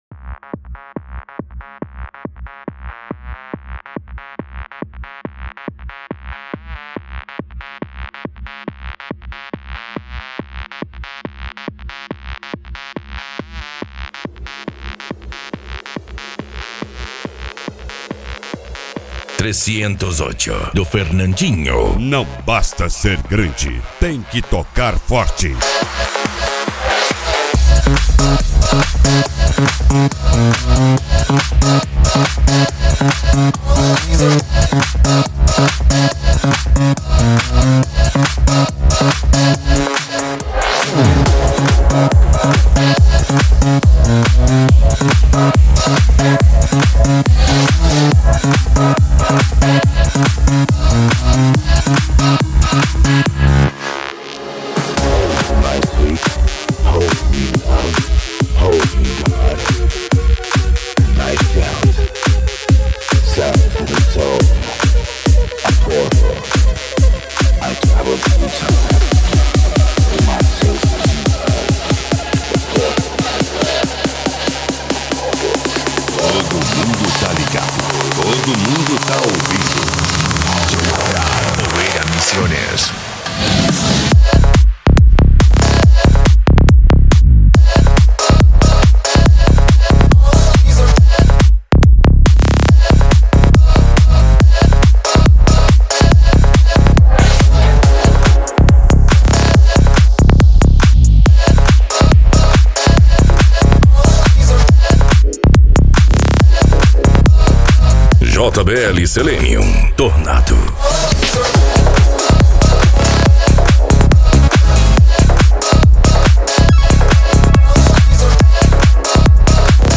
Bass
Cumbia
Funk
PANCADÃO
Psy Trance
Remix